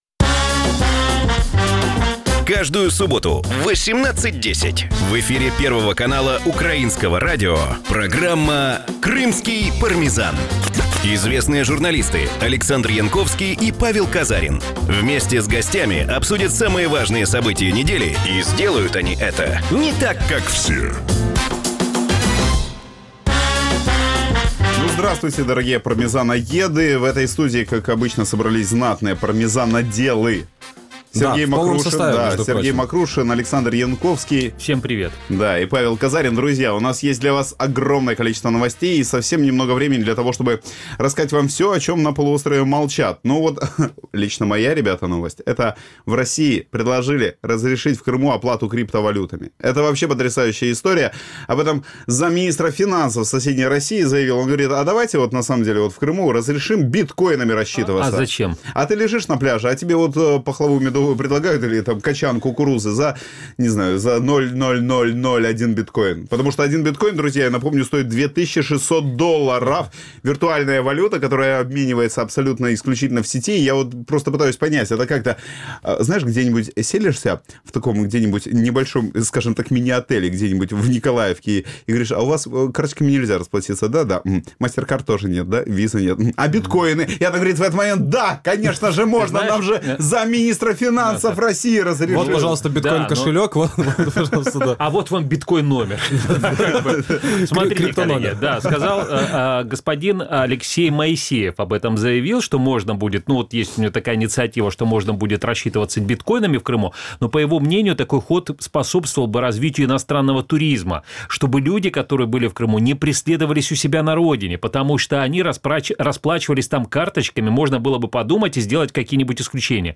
обсуждают главные события уходящей недели в Крыму. Почему в Крыму строительство дорог обходится в миллиарды? Сколько надо платить туристам за отдых в Крыму?